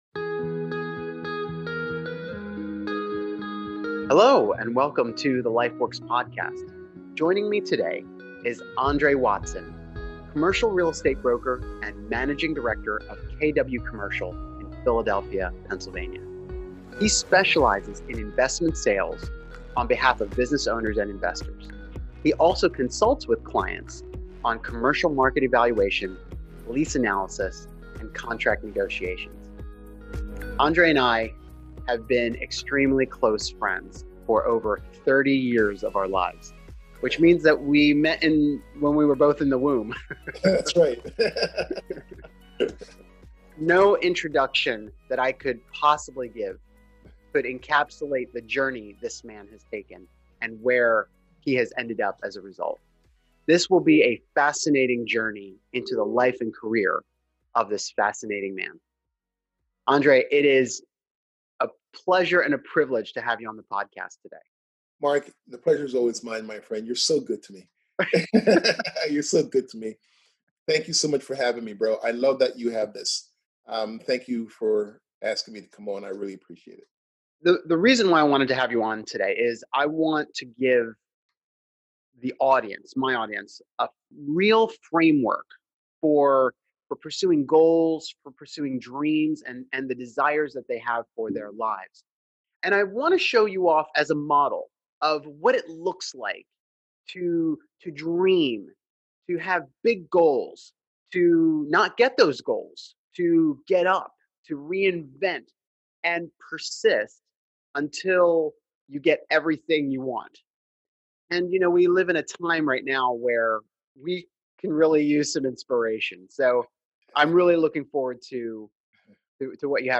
This interview is full of humor, depth, wisdom, and inspiration. If you want to see two close friends of 30+ years bantering about the important things in life, you are in for a treat.